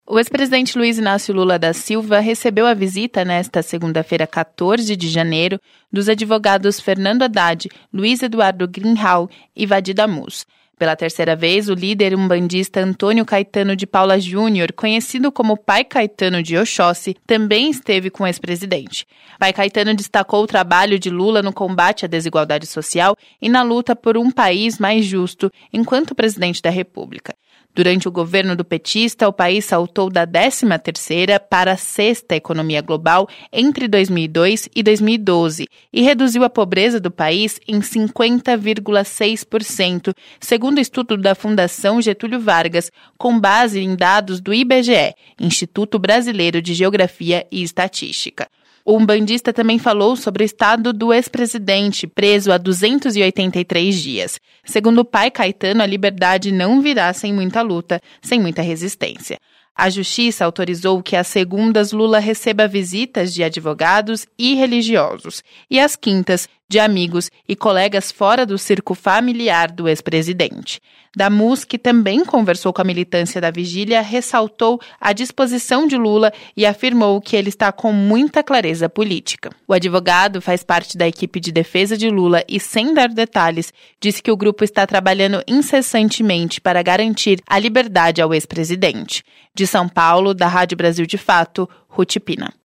Em diálogo na Vigília Lula Livre após a visita